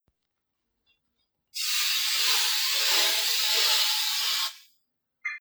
Salida de chorro de vapor o agua a presión
Grabación sonora del sonido de la salida a presión de un chorro de vapor de agua o de agua simplemente
Sonidos: Agua
Sonidos: Industria